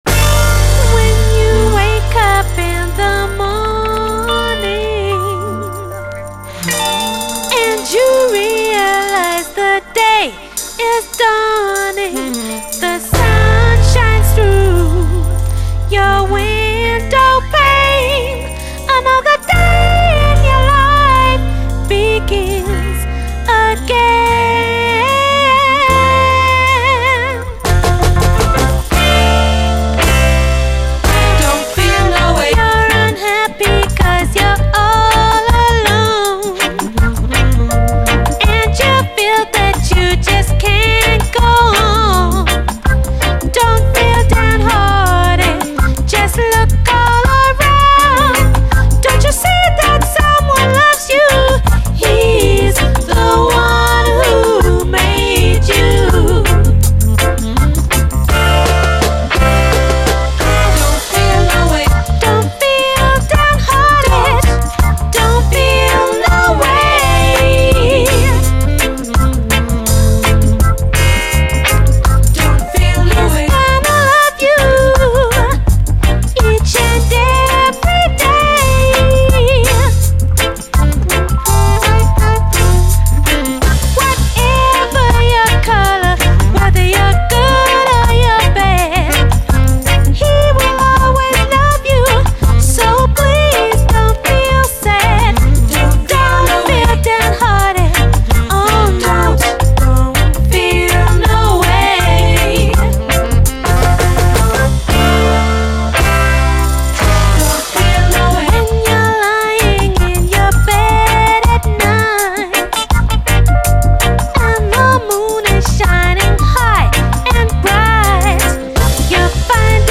REGGAE
ド頭からインパクト大、UKラヴァーズを代表する一曲です！後半はダブに接続。